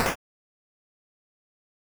put down.wav